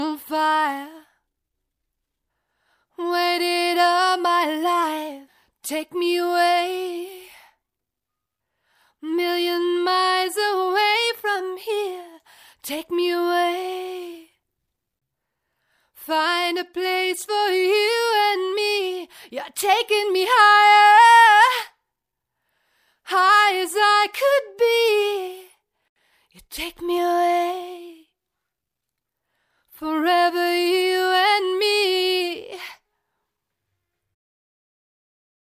Demo of the acapella here :